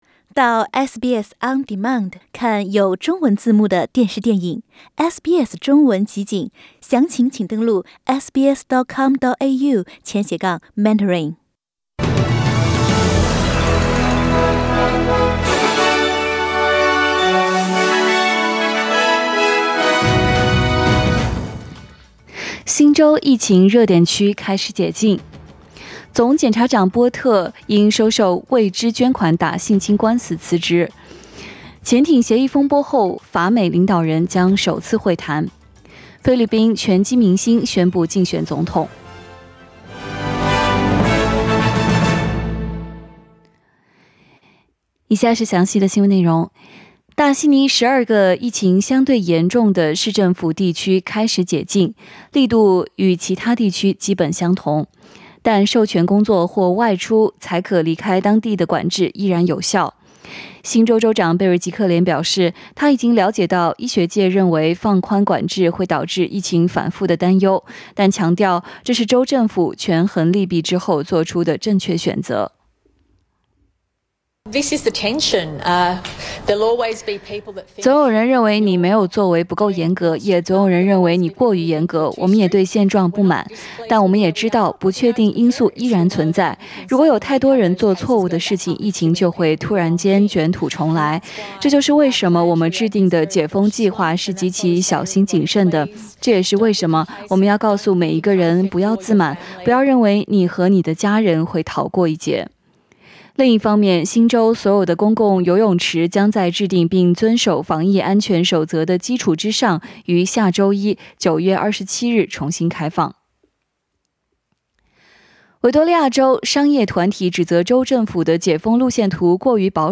SBS早新闻 （9月20日）